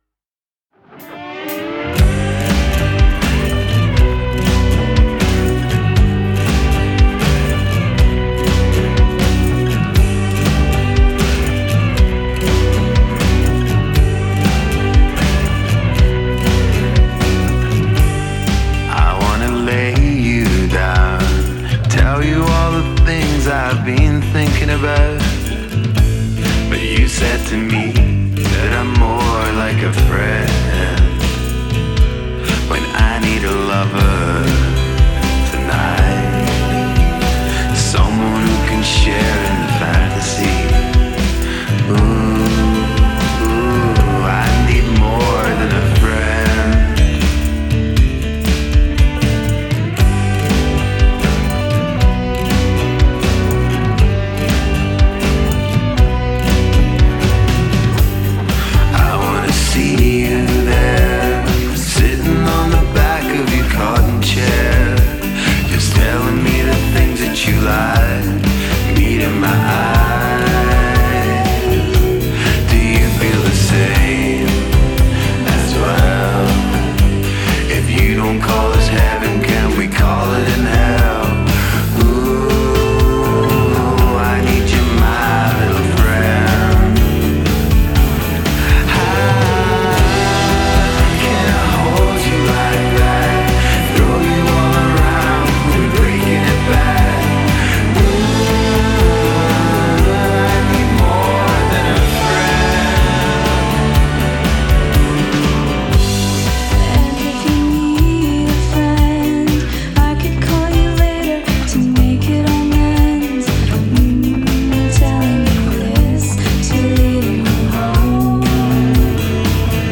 Genre: acoustic, experimental, folk rock